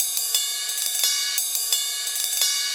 Ride Loops (7).wav